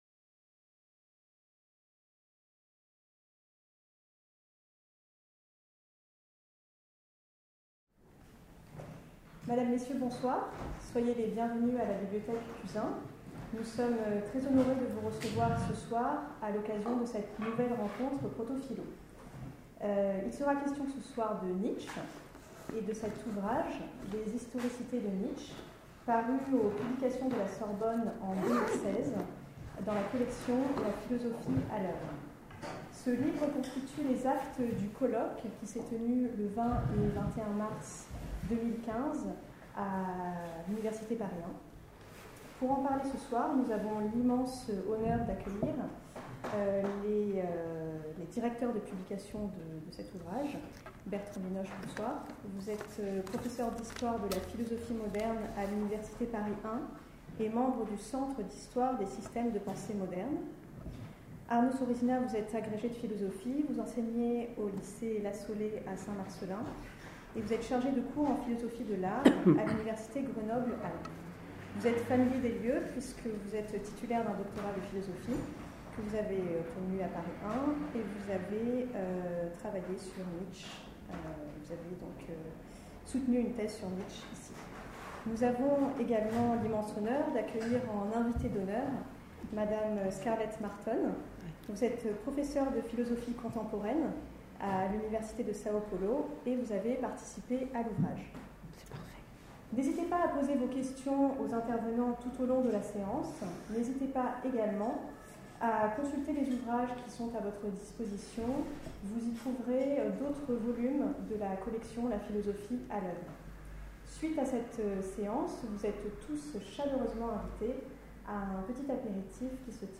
Cette rencontre était organisée par la bibliothèque Cuzin de l'Université Paris 1 panthéon-Sorbonne.